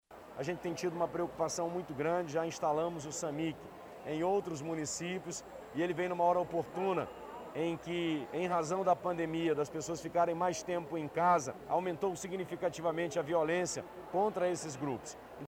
Sonora-Wilson-Lima-governador-1.mp3